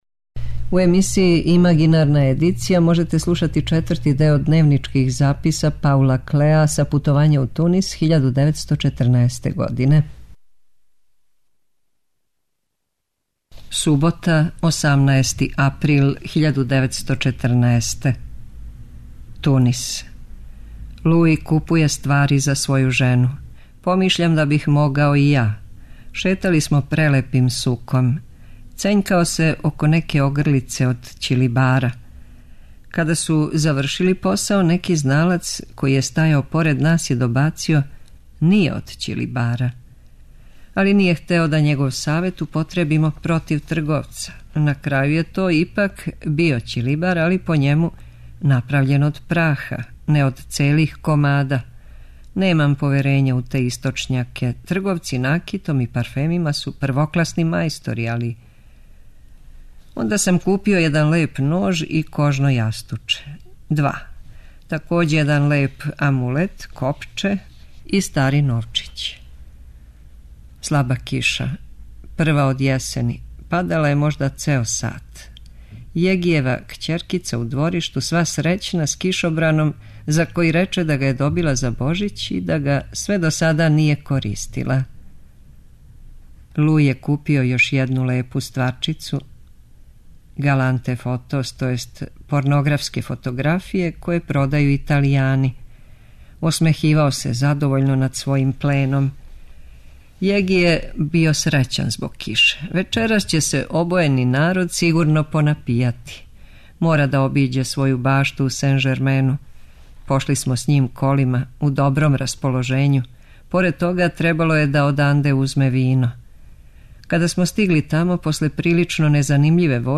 преузми : 6.03 MB Рефлексије Autor: Уредници Трећег програма У емисијама РЕФЛЕКСИЈЕ читамо есеје или научне чланке домаћих и страних аутора.